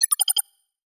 Holographic UI Sounds 2.wav